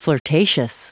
Prononciation du mot flirtatious en anglais (fichier audio)
flirtatious.wav